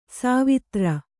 ♪ sāvitra